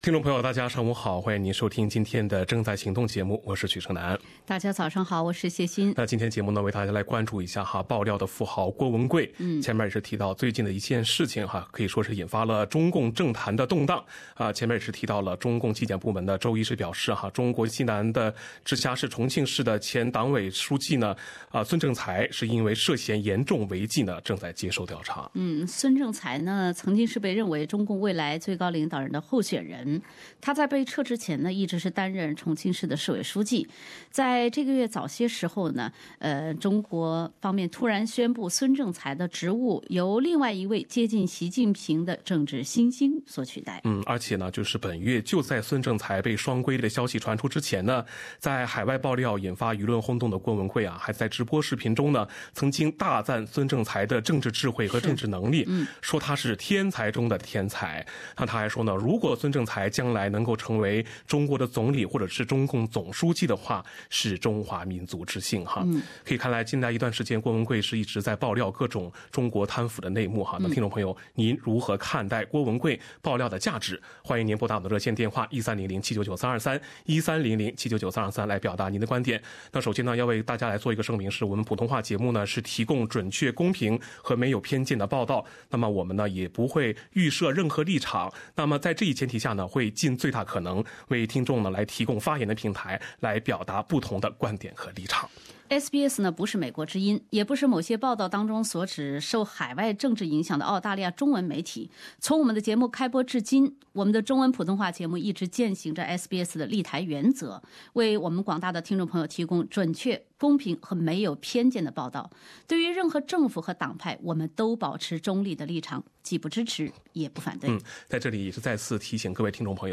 SBS中文普通话节目听众热议：如何看待郭文贵爆料的价值？